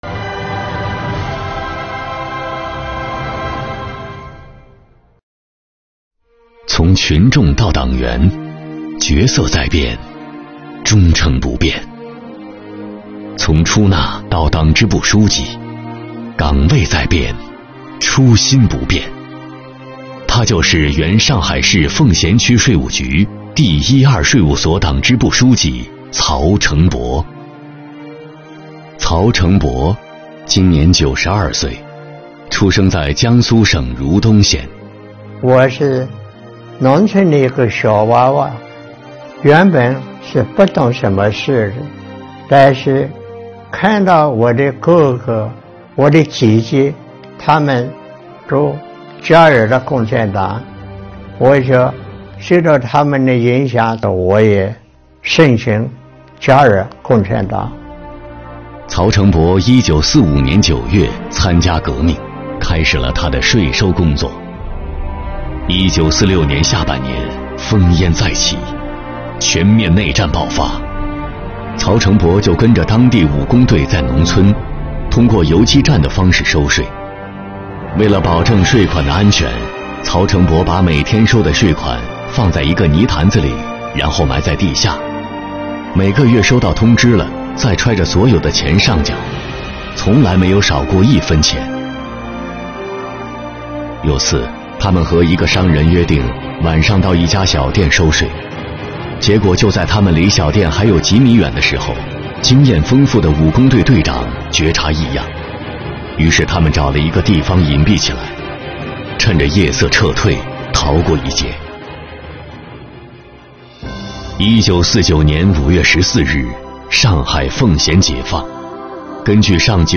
【关注】爱党爱国、爱税爱岗！听老一辈税务人讲述他们的税收故事